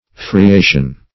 Friation \Fri*a"tion\, n.